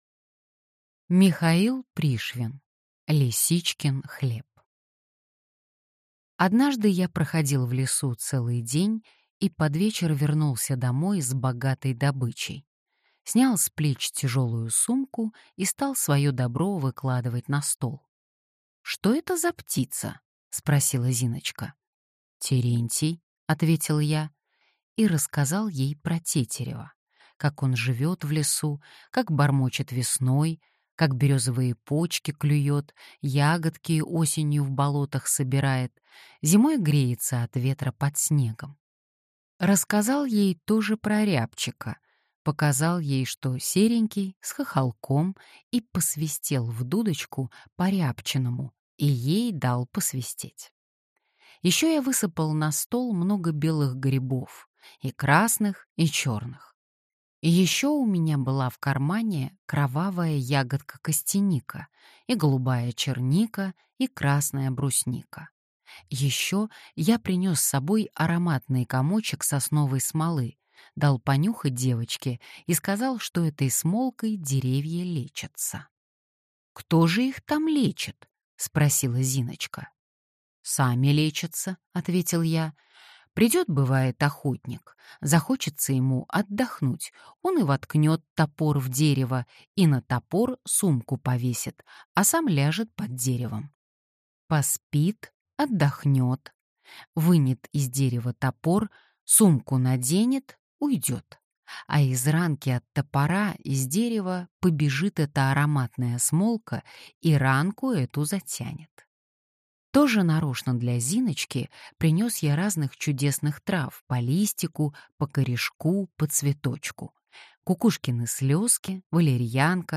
Аудиокнига Лисичкин хлеб. Рассказы | Библиотека аудиокниг